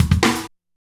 drums short03.wav